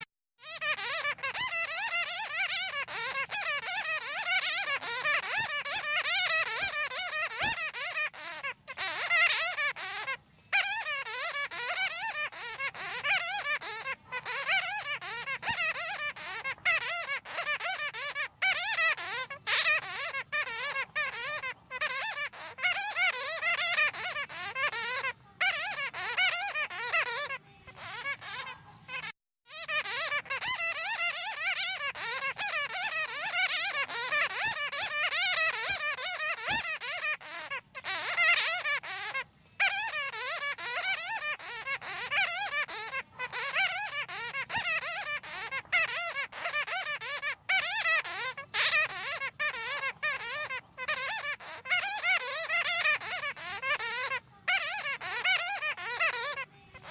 Tiếng Dơi kêu MP3